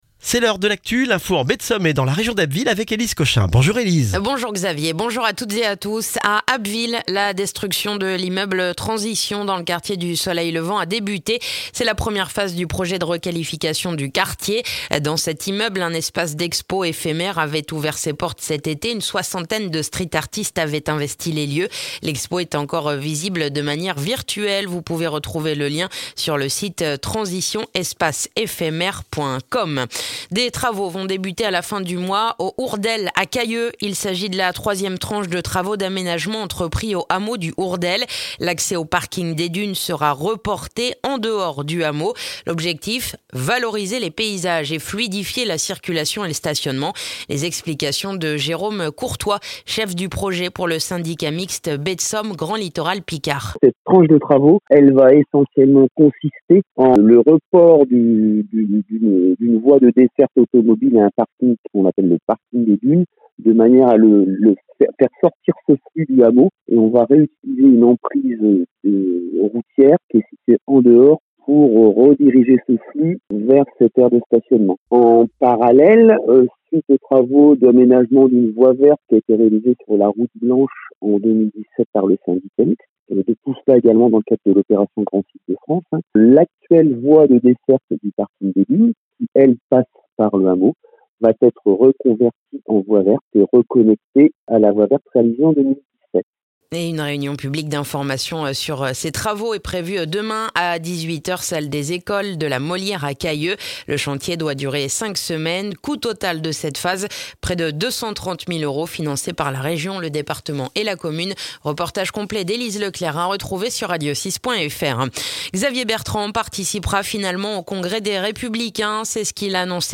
Le journal du mardi 12 octobre en Baie de Somme et dans la région d'Abbeville